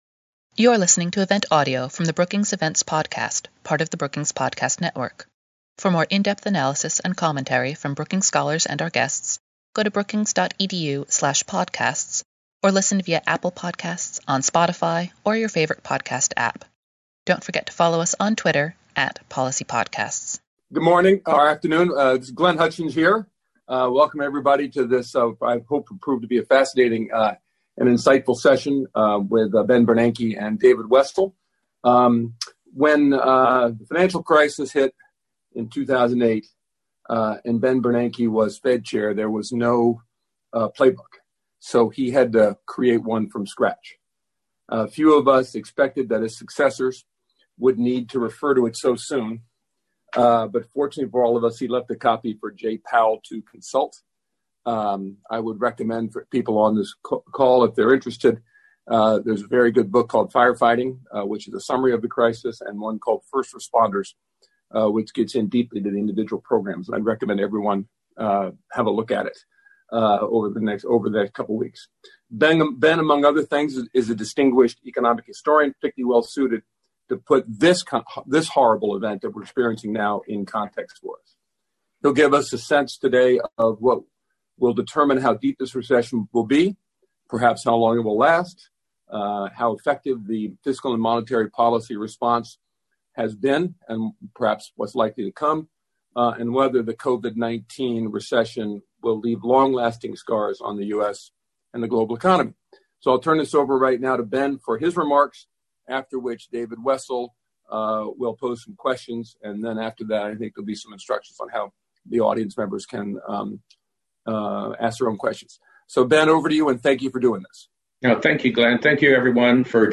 Webinar: Former Fed Chair Ben Bernanke weighs in on the economic response to COVID-19 | Brookings